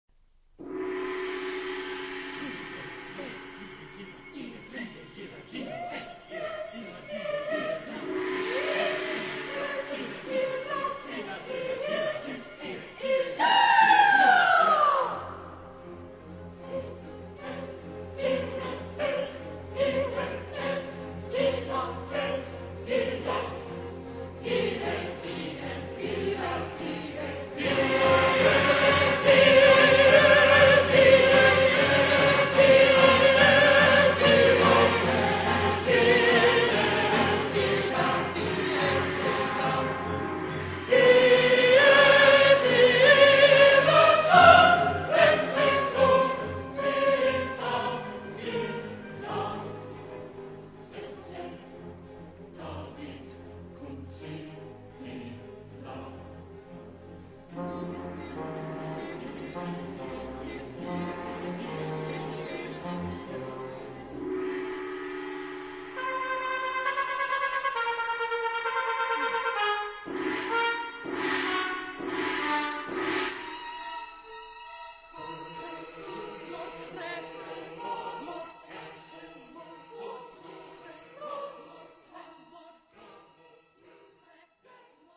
Sagrado. contemporáneo.